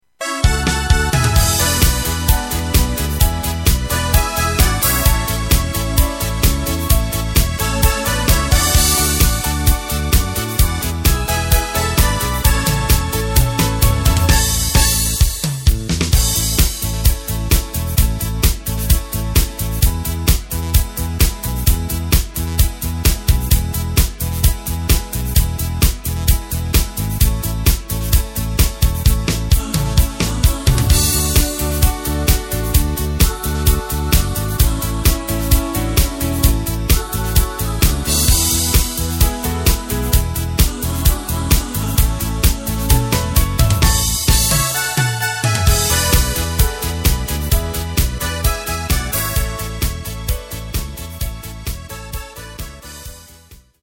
Tempo:         130.00
Tonart:            A
Schlager aus dem Jahr 2003!